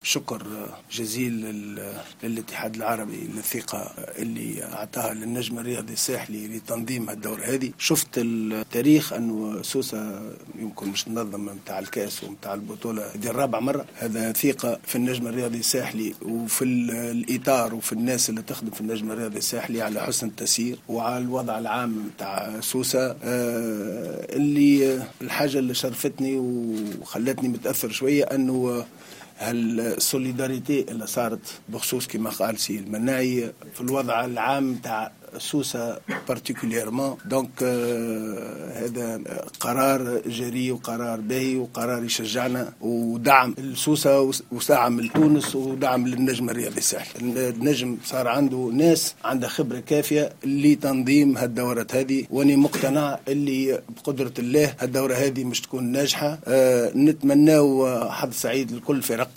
إنعقدت اليوم ندوة صحفية بإحدى نزل مدينة سوسة لتوقيع عقد إستضافة مدينة سوسة لبطولة الأمير فيصل بن فهد للأندية البطلة لكرة اليد رجال و سيدات.